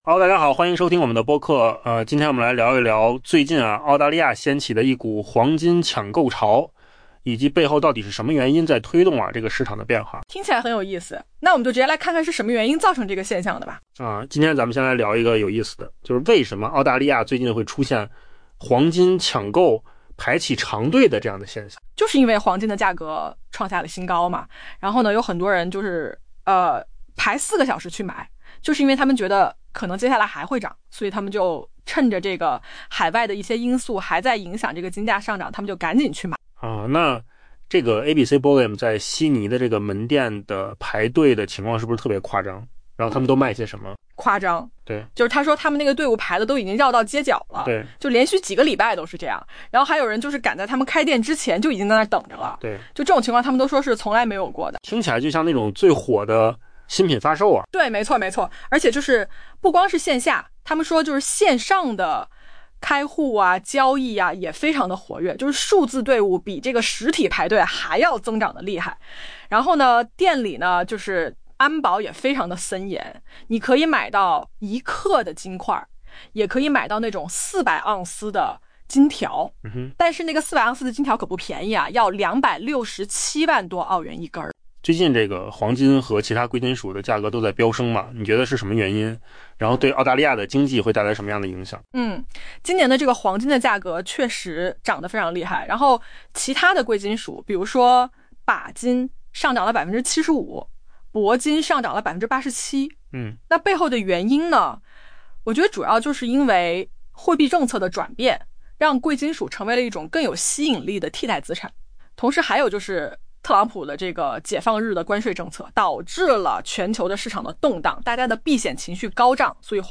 AI 播客：换个方式听新闻 下载 mp3 音频由扣子空间生成 随着黄金价格创下历史新高，澳大利亚人不惜排队长达 4 小时，只为抢购一小块黄金。